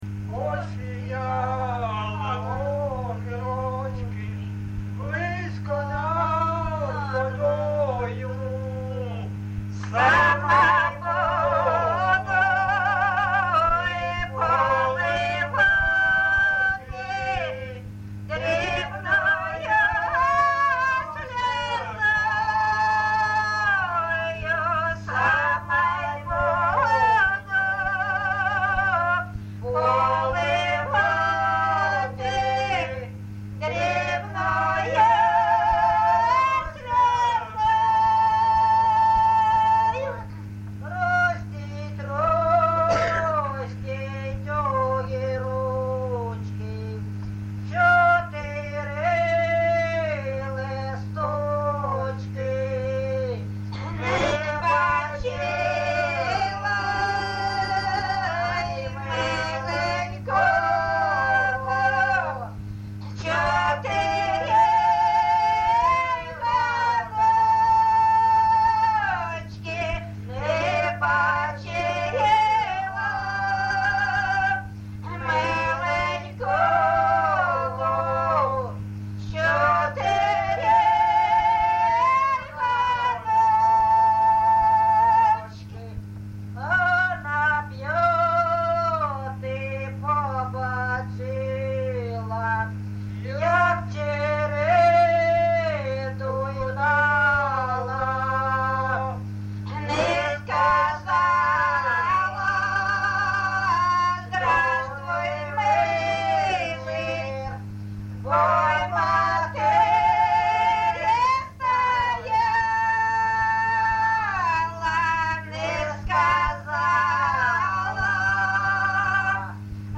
ЖанрПісні з особистого та родинного життя
Місце записус. Бузова Пасківка, Полтавський район, Полтавська обл., Україна, Полтавщина